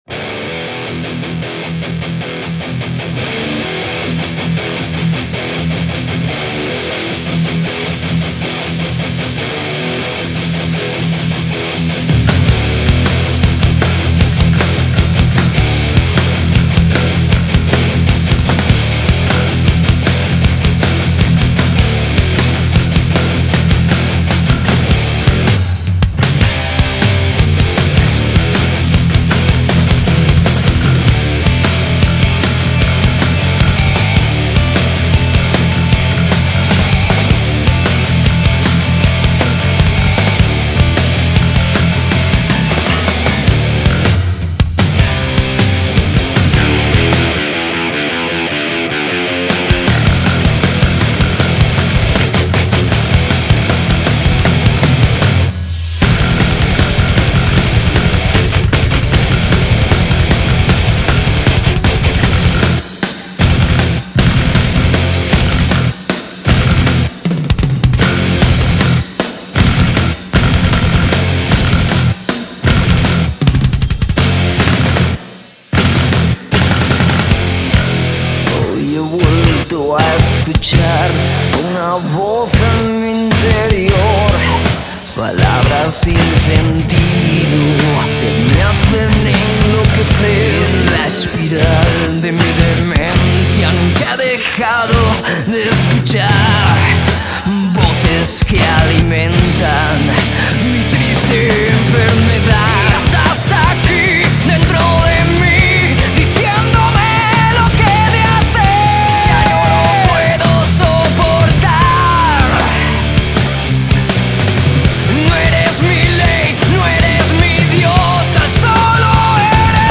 Guitarra/Voz
Guitarra Solista
Bajo
Batería